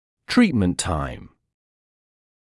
[‘triːtmənt taɪm][‘три:тмэнт тайм]время лечения